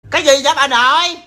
Nhạc nền, nhạc hiệu ứng để cắt ghép edit video